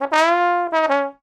MANNIE_FRESH_trombone_second_line_fancy.wav